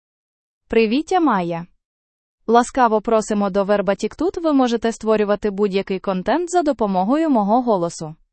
Maya — Female Ukrainian AI voice
Maya is a female AI voice for Ukrainian (Ukraine).
Voice sample
Listen to Maya's female Ukrainian voice.
Maya delivers clear pronunciation with authentic Ukraine Ukrainian intonation, making your content sound professionally produced.